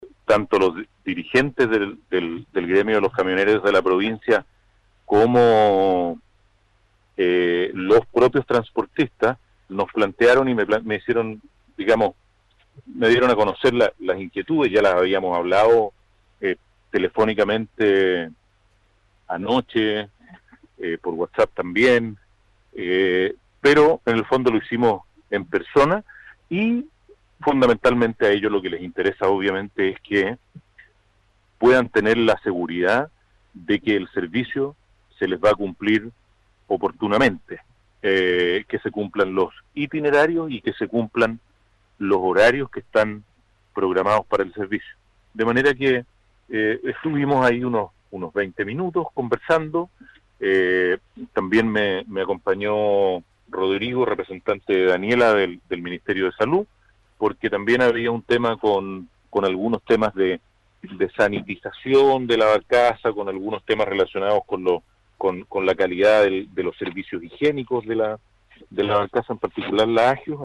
El gobernador Carrasco detalló que los requerimientos de los camioneros se centran en el cumplimiento de los itinerarios de parte de Naviera Austral.